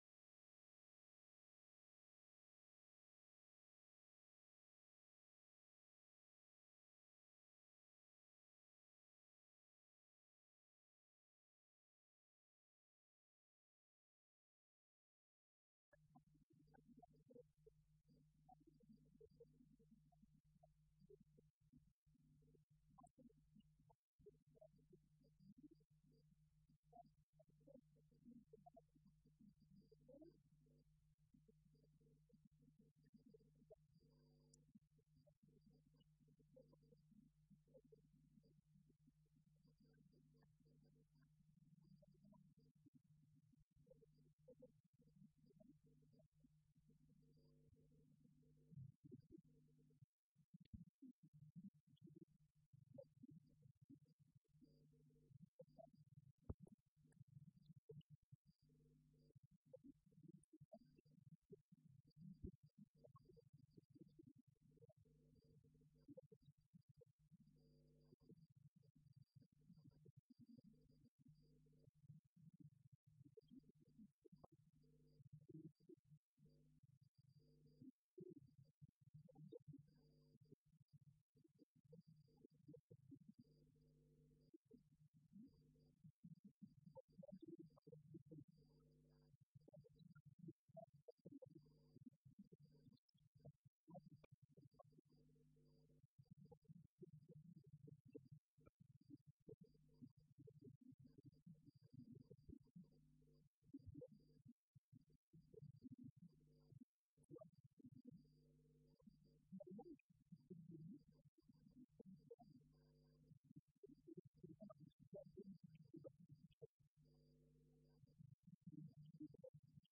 Colloque L’Islam et l’Occident à l’époque médiévale.